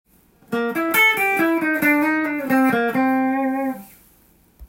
⑤のフレーズは、スケールというよりコードトーンを弾きながら
メジャーペンタトニックスケールとマイナーペンタトニックスケールを